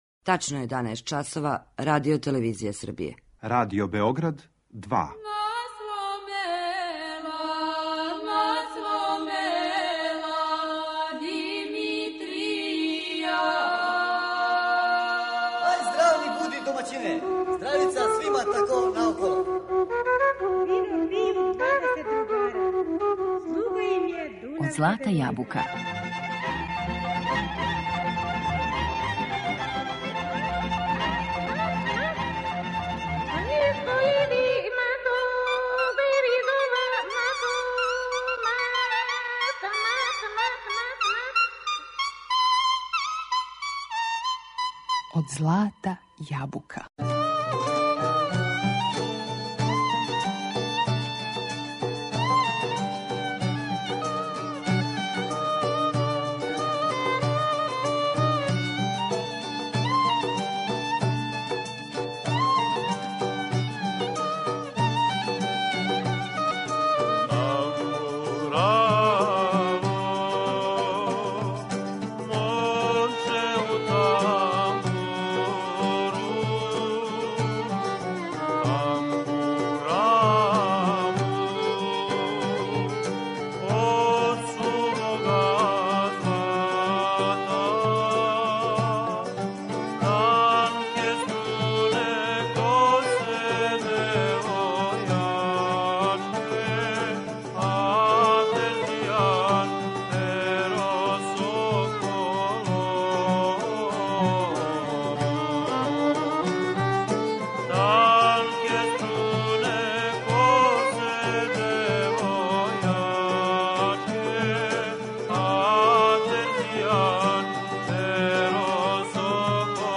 Tамбурa
Прескочићемо уобичајени увод који подразумева порекло, развој инструмента и најистакнутије композиторе и вође тамбурашких састава да би се препустили слушању звука овог и сродних му инструмената, улогу у различитим формацијама што у приватној, што у јавној сфери, а највише савременог музичког извођења.